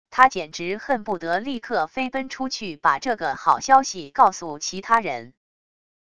他简直恨不得立刻飞奔出去把这个好消息告诉其他人wav音频生成系统WAV Audio Player